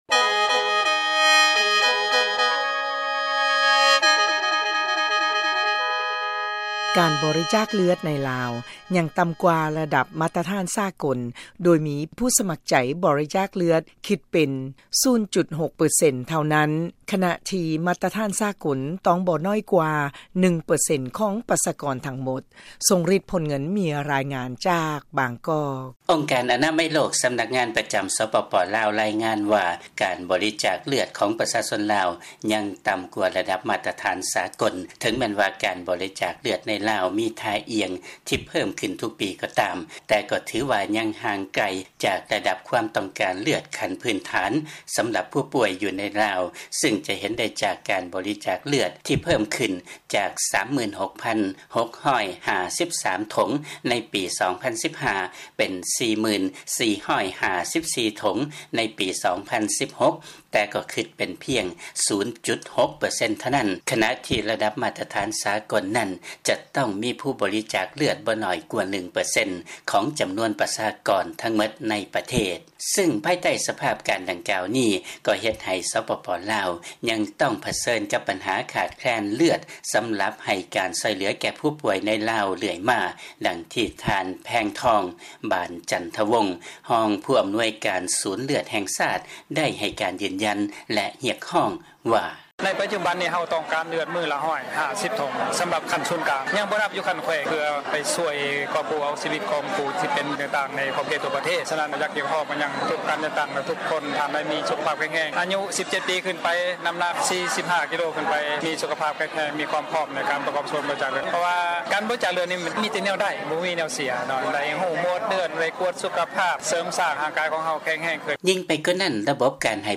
ຟັງລາຍງານ ການບໍລິຈາກເລືອດໃນ ລາວ ຍັງຕໍ່າກວ່າ ລະດັບມາດຕະຖານ ສາກົນ